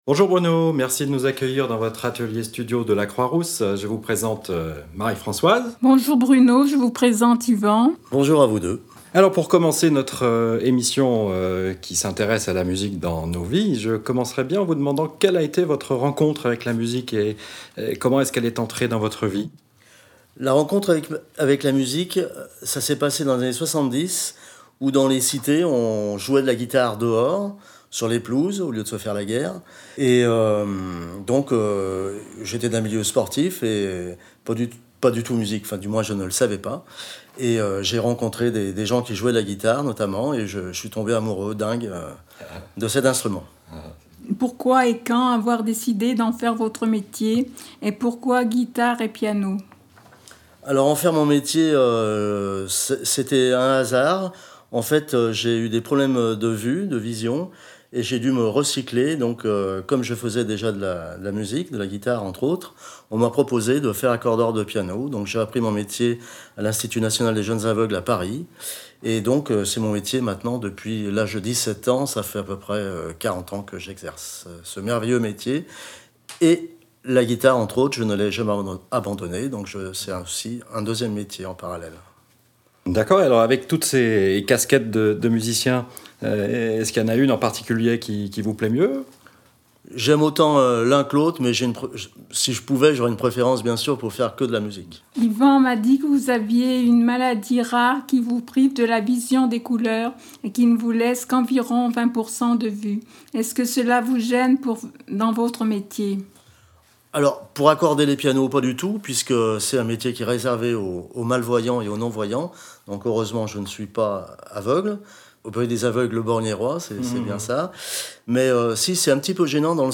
Ainsi, à partir du thème choisi, les participants vont à la rencontre de personnalités aux métiers variés et réalisent des interviews. C’est le cas ici, avec un témoignage sur le thème de la musique.